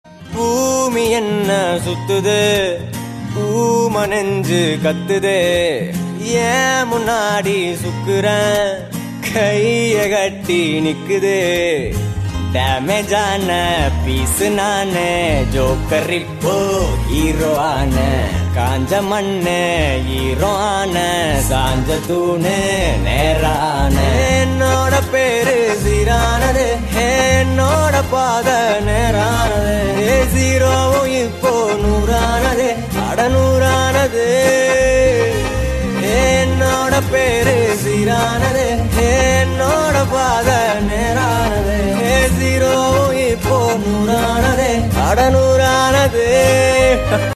tamil ringtonelove ringtonemelody ringtoneromantic ringtone
best flute ringtone download